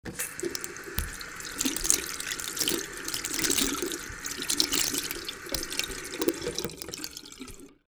Washing Hands
Washing Hands.wav